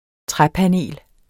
Udtale [ ˈtʁapaˌneˀl ]